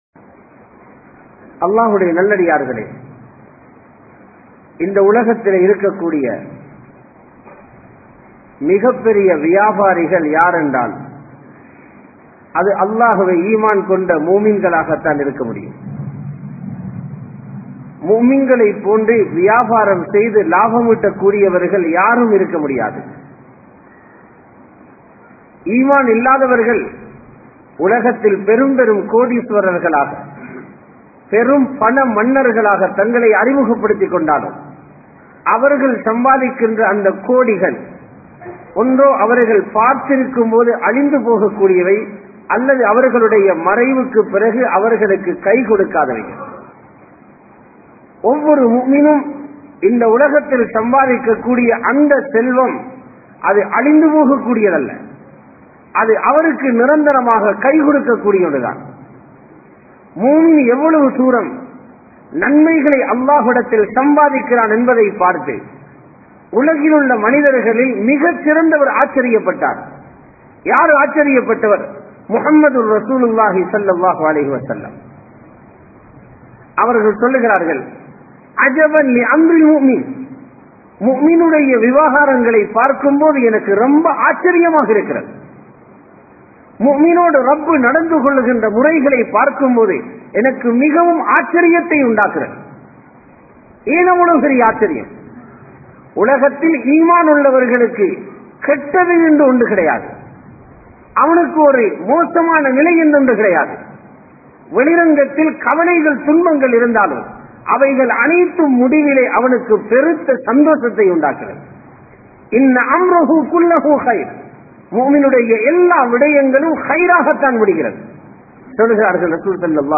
Muslim Viyafaarien Panpuhal (முஸ்லிம் வியாபாரியின் பண்புகள்) | Audio Bayans | All Ceylon Muslim Youth Community | Addalaichenai
Samman Kottu Jumua Masjith (Red Masjith)